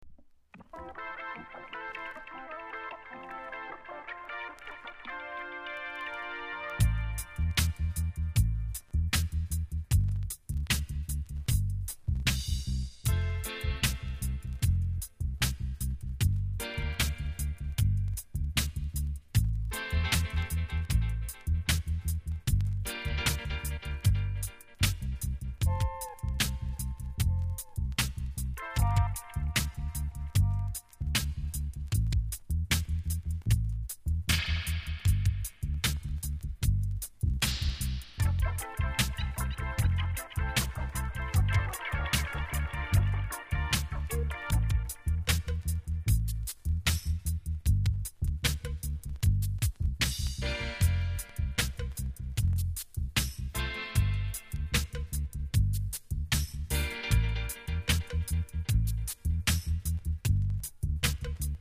サイドB DUB